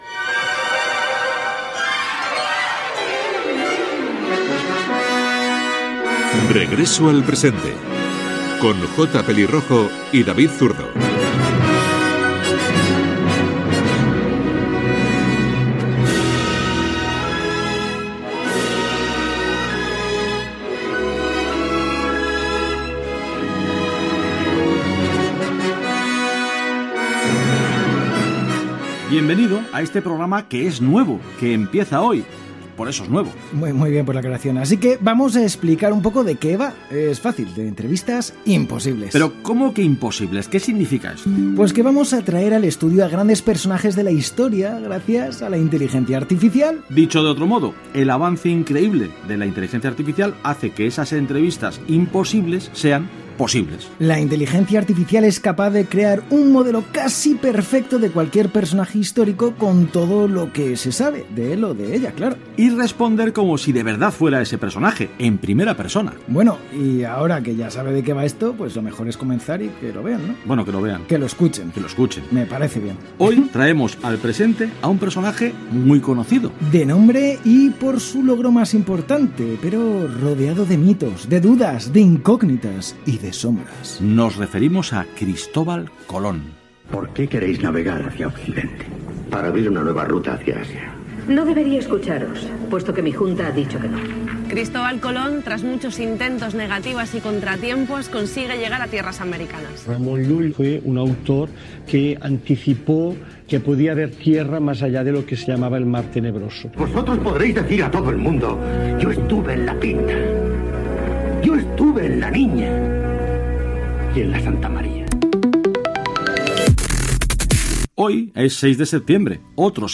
Careta, inici del primer programa, presentació del que s'oferirà: entrevistes fictícies, fetes mitjançant la inteligència artificial. Dades sobre Cristóbal Colón i fragment d'una entrevista a l'almirall descobridor de les Amèriques.
Entreteniment